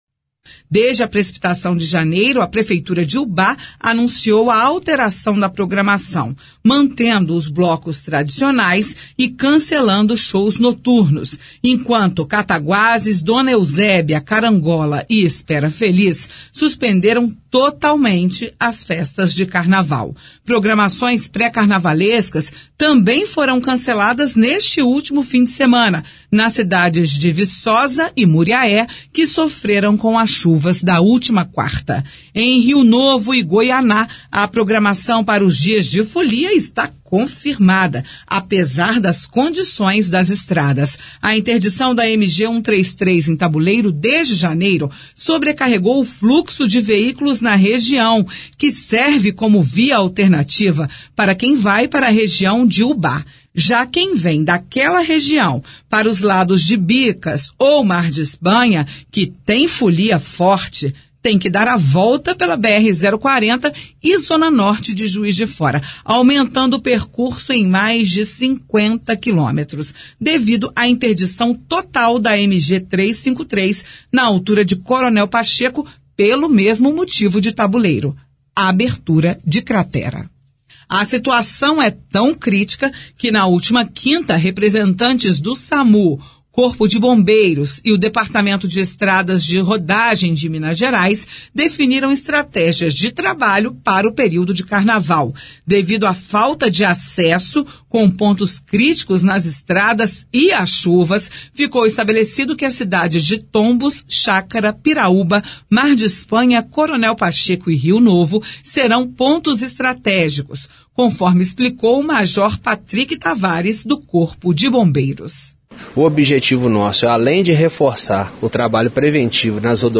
A reportagem da Itatiaia fez um levantamento sobre como fica a folia na região. E as chuvas são fator decisivo para a programação.